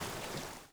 SPLASH_Subtle_05_mono.wav